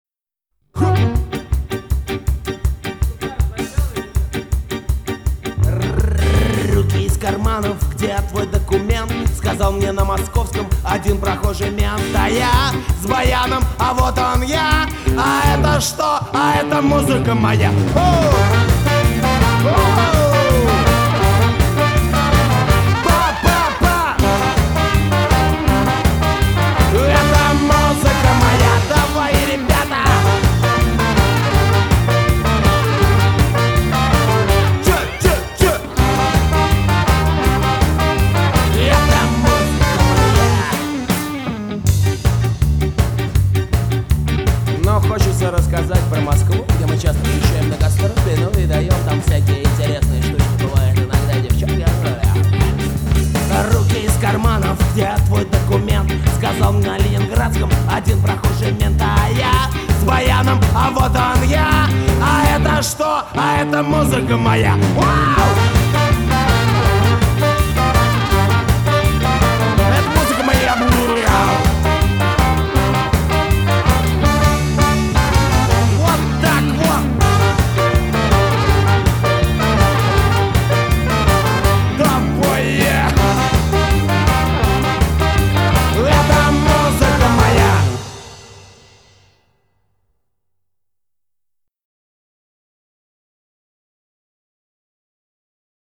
И выкрутасы этой деревянной штуковины под удалую музыку ...И уже дворец Павла выглядит совсем не зловещим, а очень даже симпатичным! )))